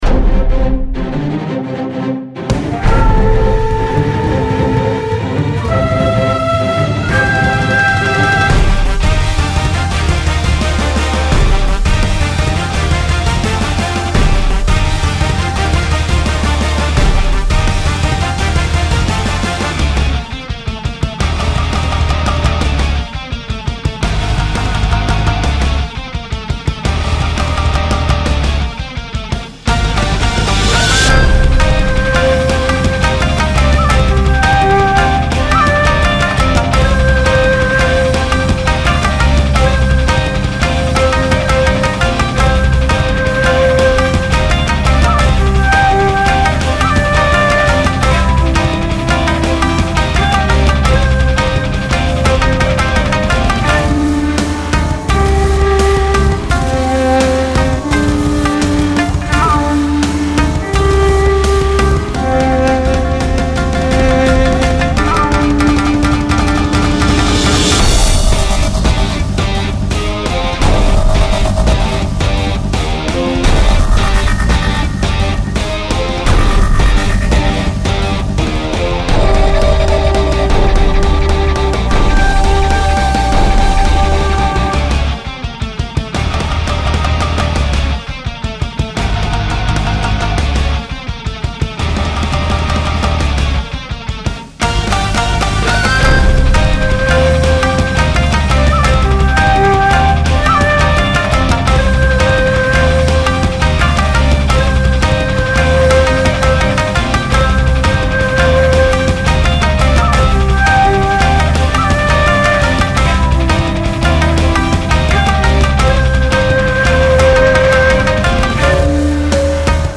雷をまとった神龍が空を裂いて舞い降りるような壮大かつ緊張感あふれるサウンドを目指しました。
尺八と三味線が主旋律を担いシンセやエレキギターが重厚なバックを支える構成となっています。
• 使用楽器：尺八、三味線、エレキギター、和太鼓など
• 曲調：和風ロック × 電子音 × ダークで激しいテンポ（BPM 120〜130）
• 構成：イントロ → 主旋律展開 → 中盤ブレイク → クライマックスへ